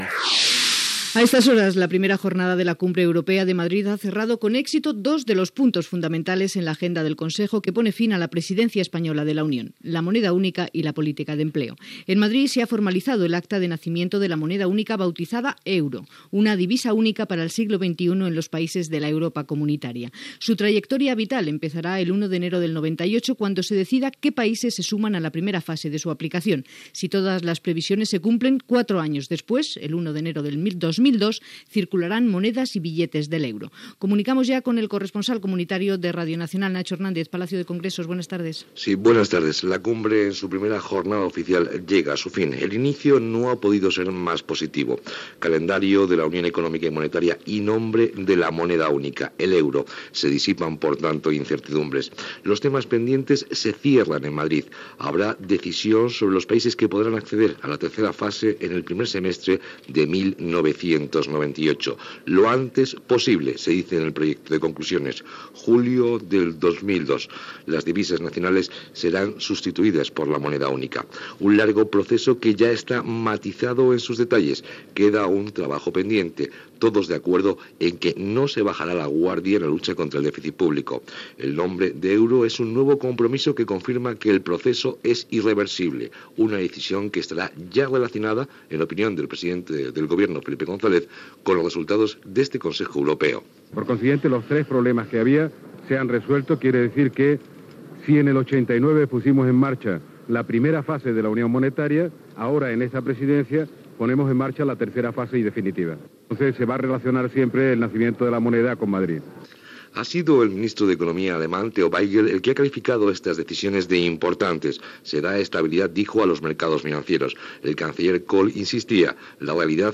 Cimera europea a Madrid per tancar el calendari per tenir una divisa única a la Unió Europea, l'euro. Declaracions del president del govern espanyol Felipe González
Informatiu